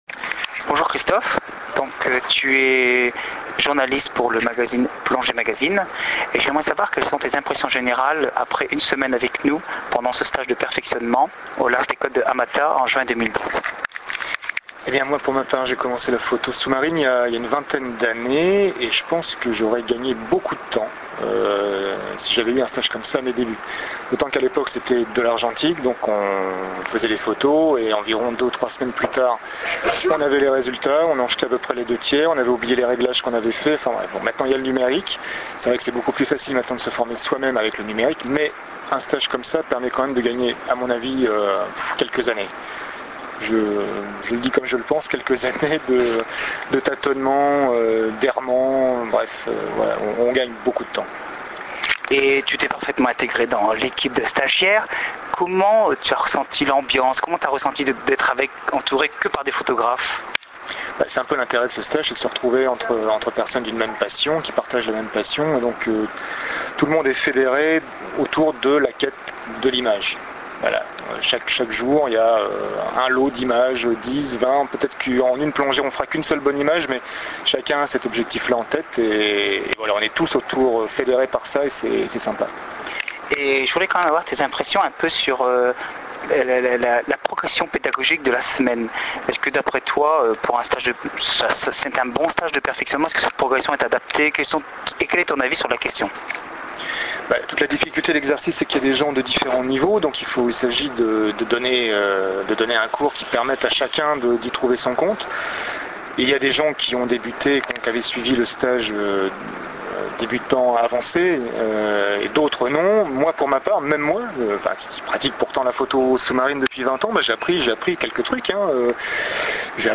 Le commentaire audio